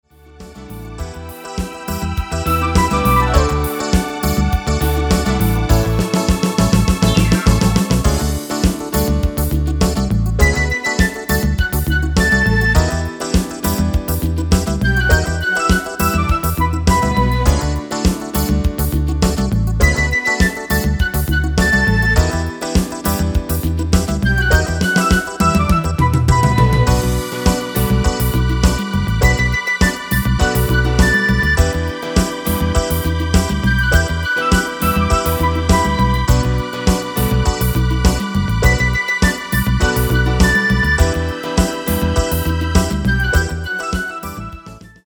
Demo/Koop midifile
Genre: Disco
Toonsoort: D
- Vocal harmony tracks
Demo's zijn eigen opnames van onze digitale arrangementen.